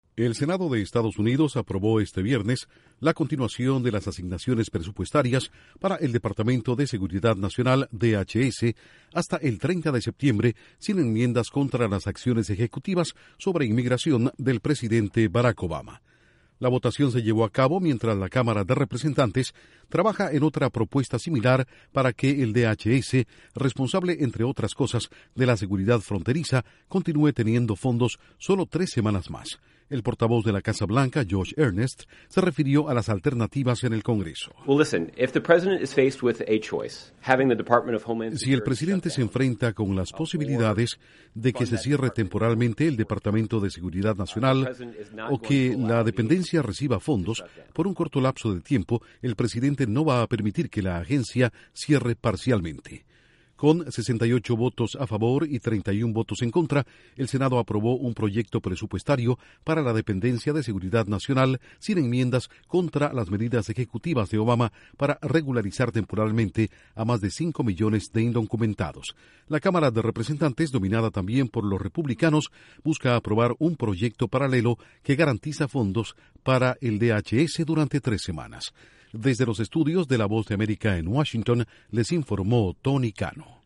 La Casa Blanca no quiere un cierre parcial del Departamento de Seguridad Nacional con enmiendas a acciones ejecutivas sobre inmigración. Informa desde los estudios de la Voz de América en Washington